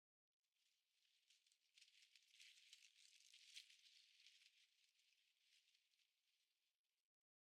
bushrustle1.ogg